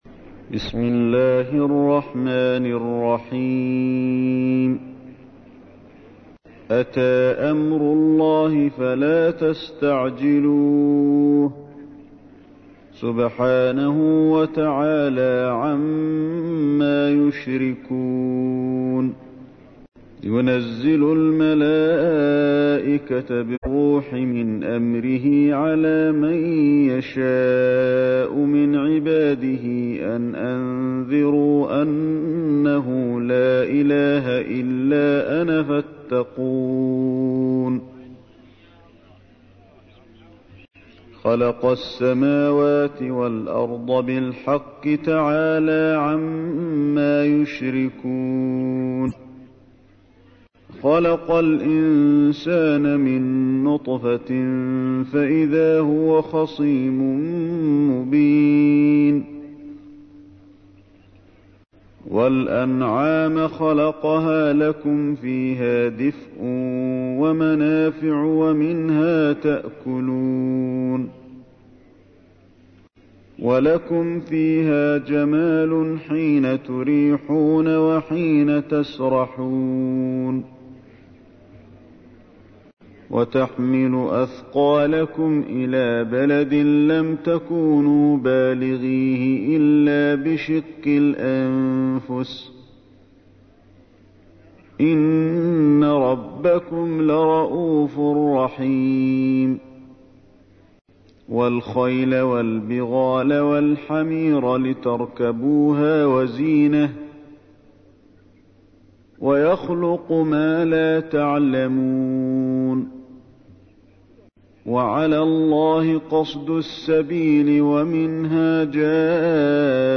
تحميل : 16. سورة النحل / القارئ علي الحذيفي / القرآن الكريم / موقع يا حسين